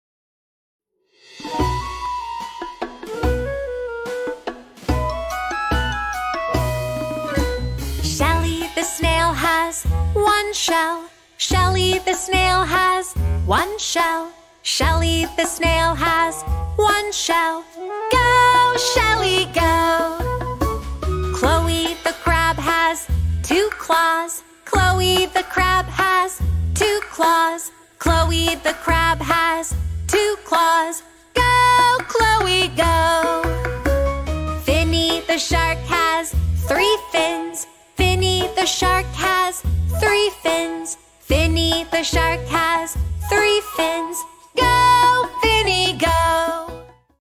song for kids